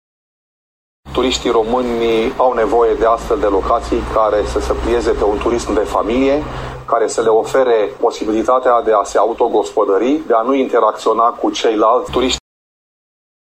propietar de cabană